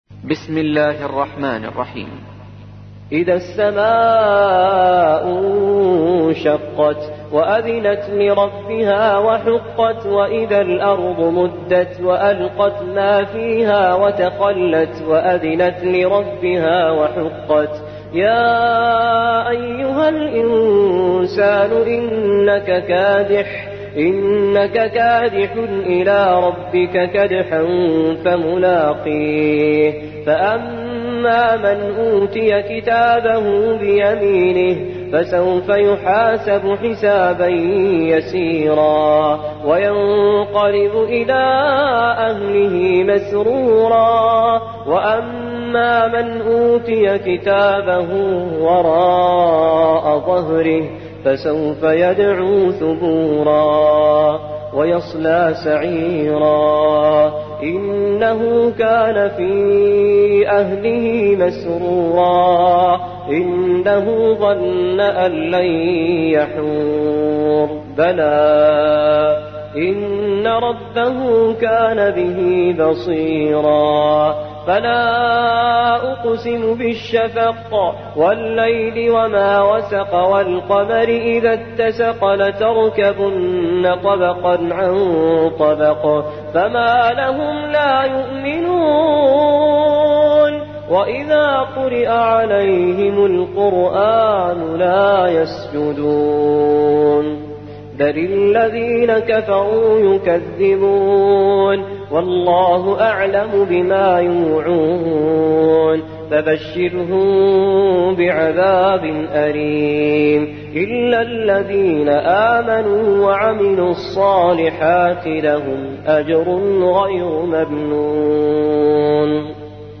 84. سورة الانشقاق / القارئ